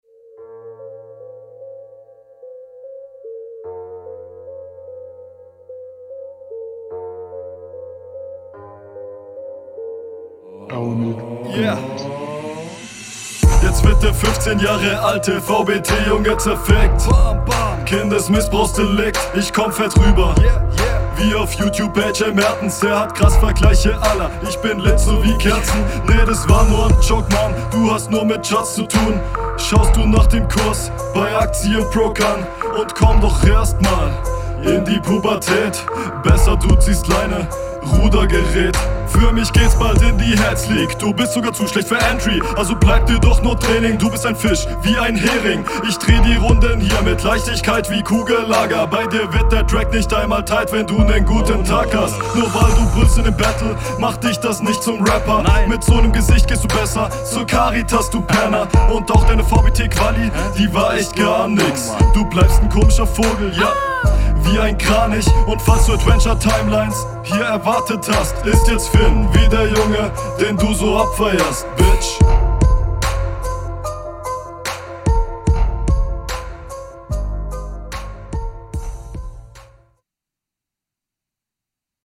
soundquali ganz nice, versteht man auch alles. stimmeinsatz okay, punchlines sind 2-3 drei hängen geblieben, …